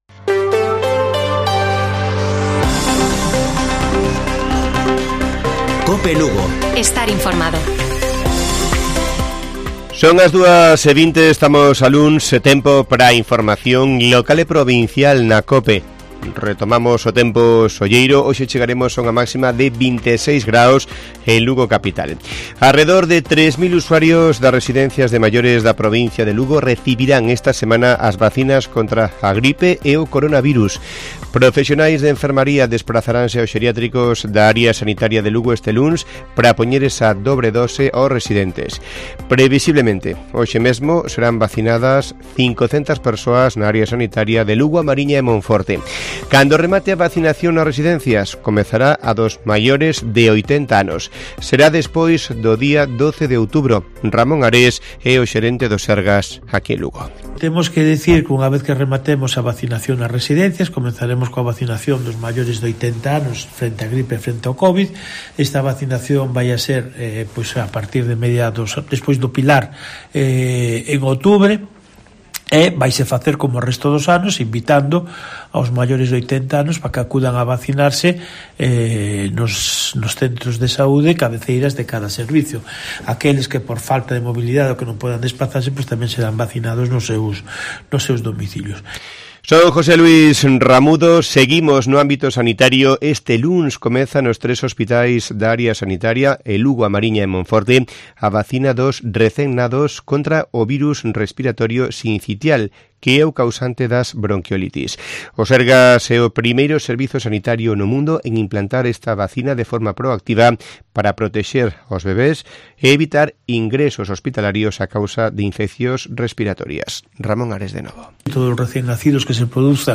Informativo Mediodía de Cope Lugo. 25 de septiembre. 14:20 horas